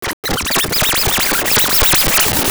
SCRATCHY 1.wav